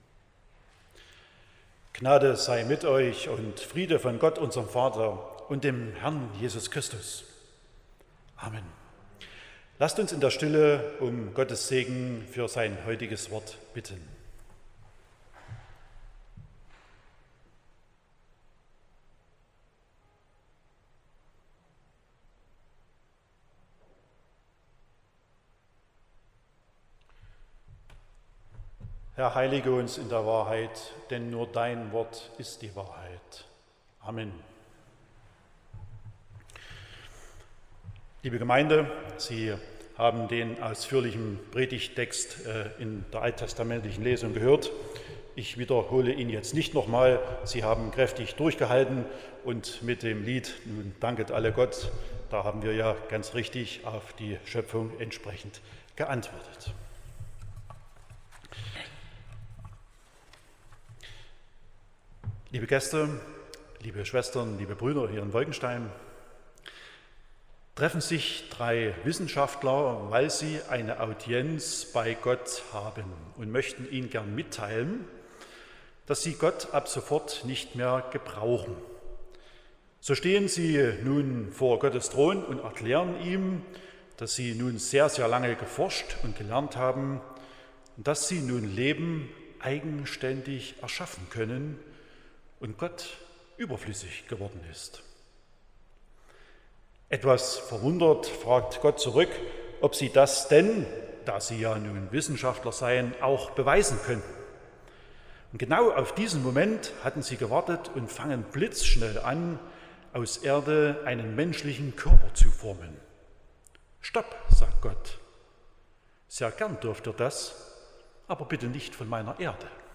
01.09.2024 – Gottesdienst
Predigt und Aufzeichnungen